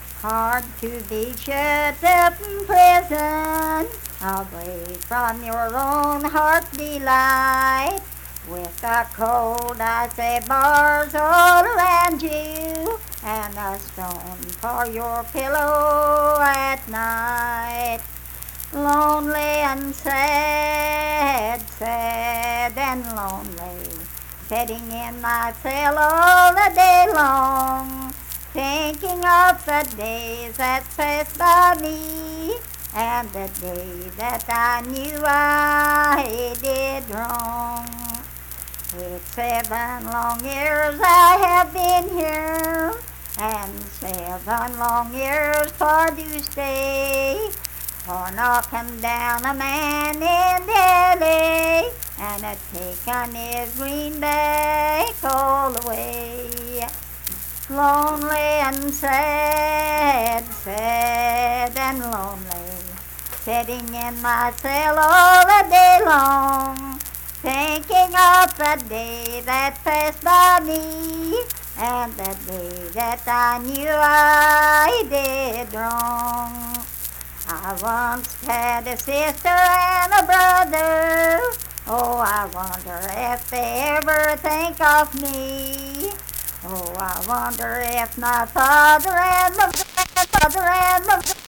Unaccompanied vocal music performance
Verse-refrain 3(4) & R(4).
Voice (sung)